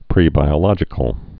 (prēbī-ə-lŏjĭ-kəl)